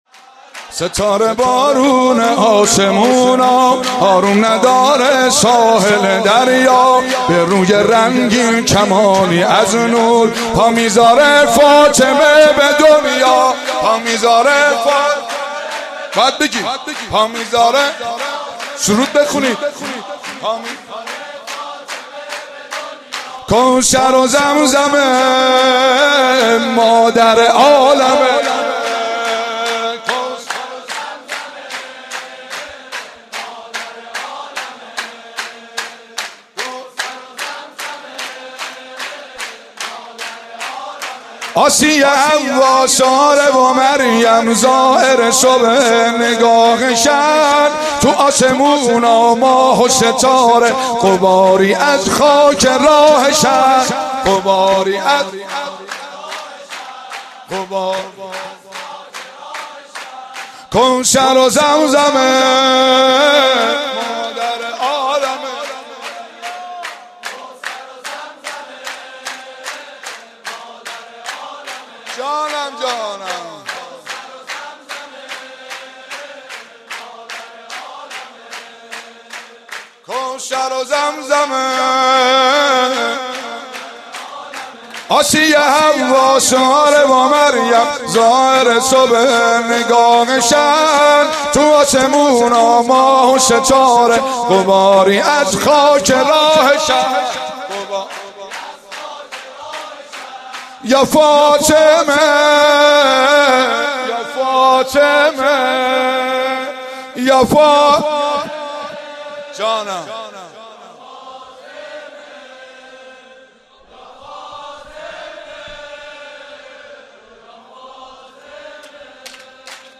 مولودی خوانی
بمناسبت میلاد با سعادت حضرت زهرا سلام الله علیها.